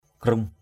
/kruŋ/ (d.) dấu; nguyên, tích xưa = signe; originel. caik krung =cK k~/ để dấu (kỷ niệm) = laisser un signe (ou souvenir). ngap krung ZP k~/ làm dấu =...